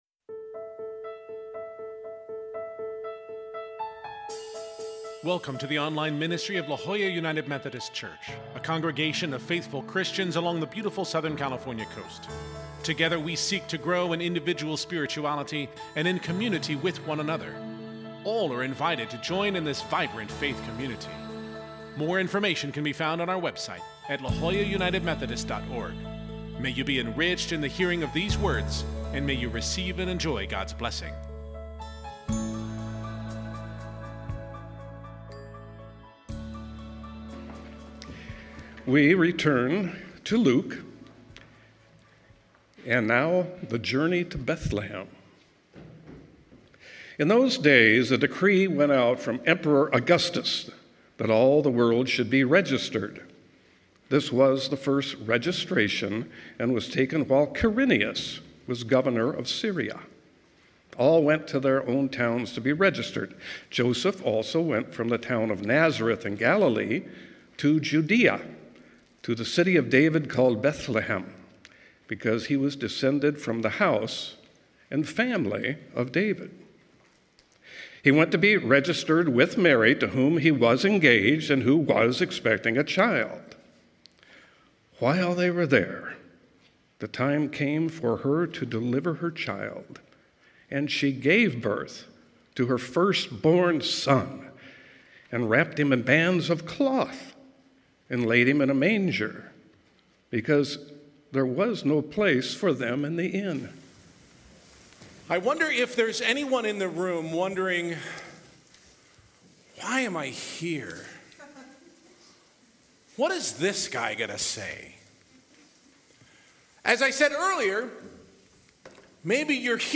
Our traditional Christmas Eve service is an essential experience with the La Jolla UMC faith community and indeed the surrounding community of La Jolla!. The Christmas story will be told through Scripture and song, and conclude with candle lighting.